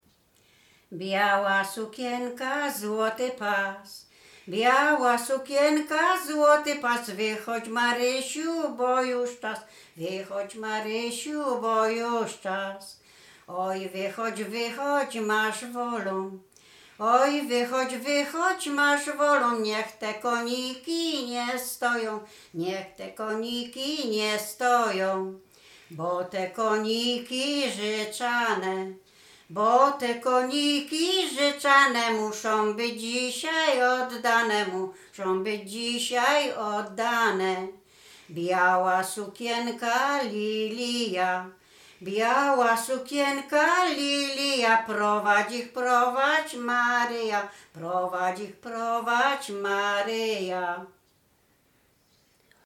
województwo łodzkie, powiat sieradzki, gmina Błaszki, wieś Mroczki Małe
wesele weselne na wyjazd do kościoła